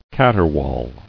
[cat·er·waul]